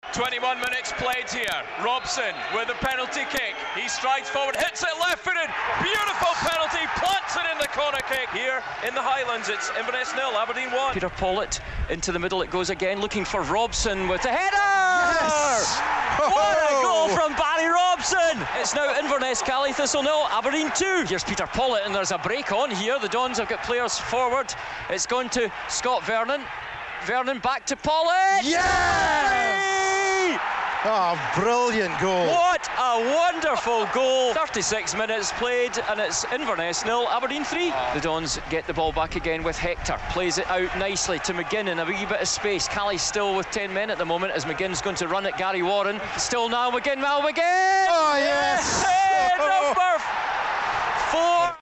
Inverness 0-4 Aberdeen (Half time) Red TV Commentary